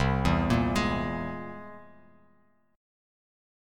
C+7 Chord
Listen to C+7 strummed